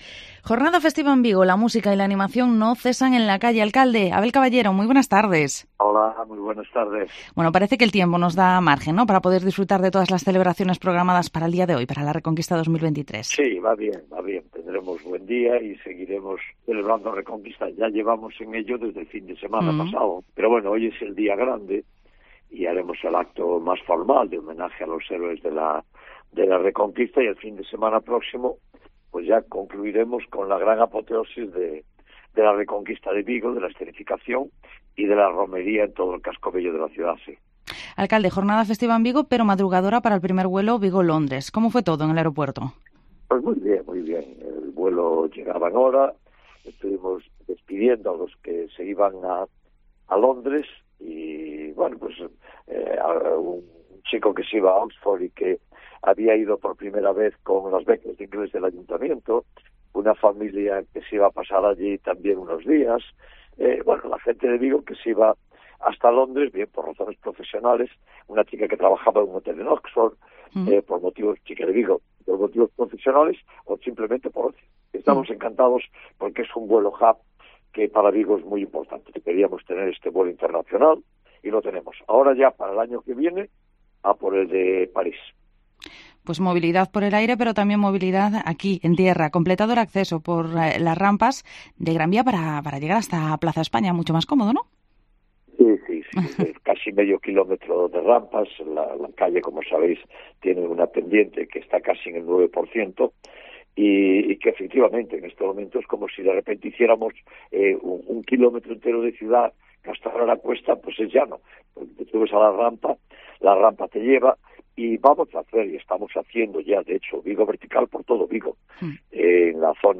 Entrevista al Alcalde de Vigo, Abel Caballero, en el Día de la Reconquista